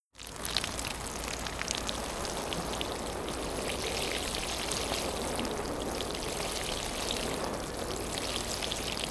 WateringCan.wav